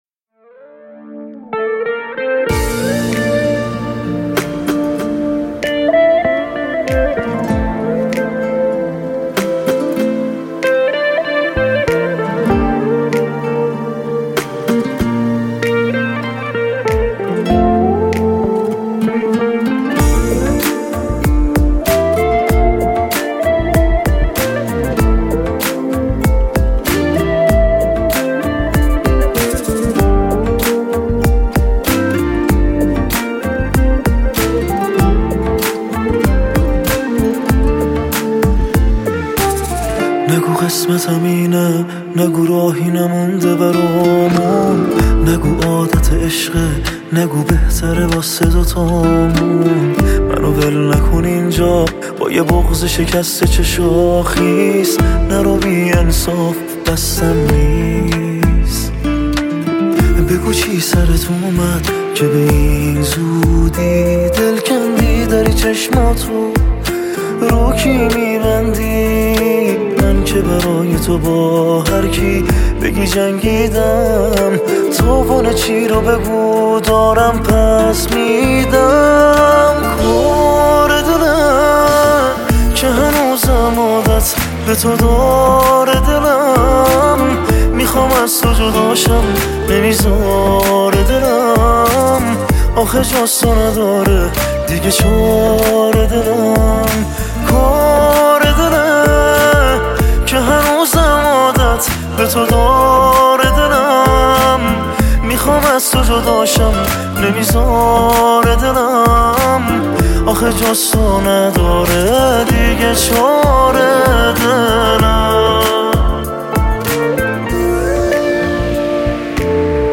پاپ عاشقانه عاشقانه غمگین پاپ